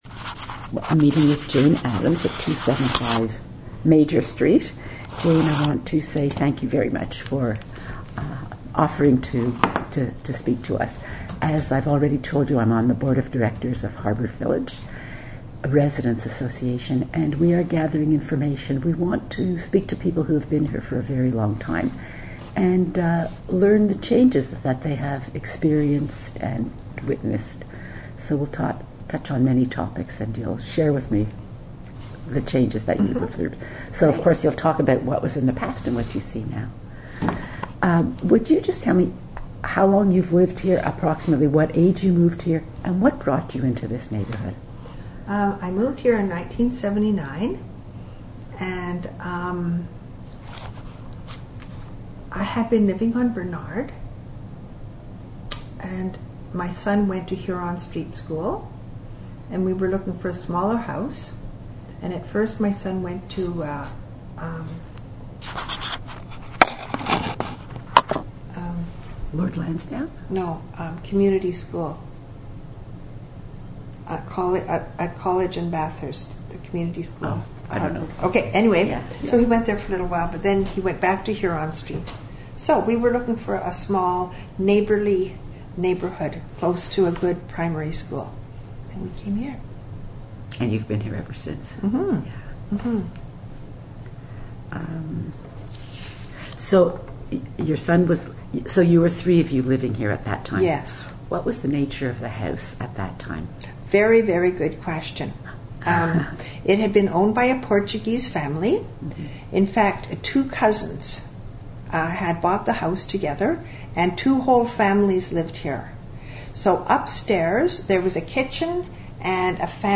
Harbord Village Oral History Project 2013